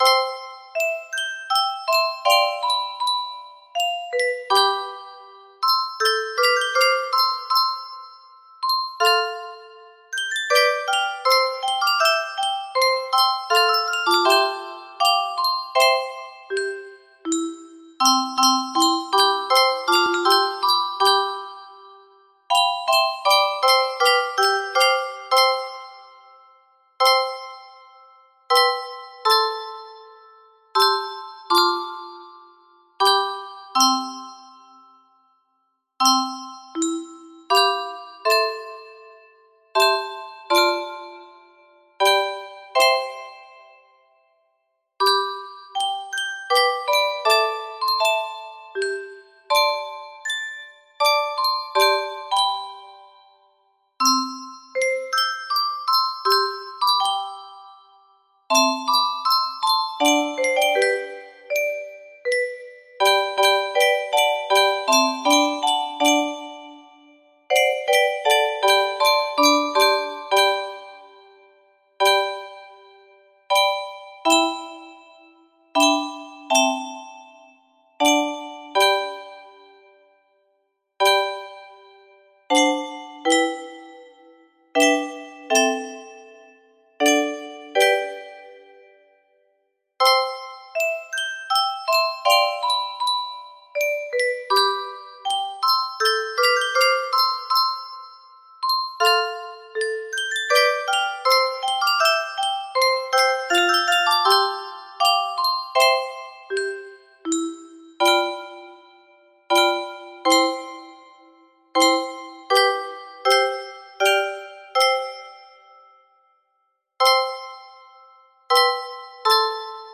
Salve Mater - Marienlied music box melody
Grand Illusions 30 (F scale)